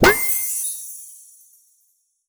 magic_pop_open_02.wav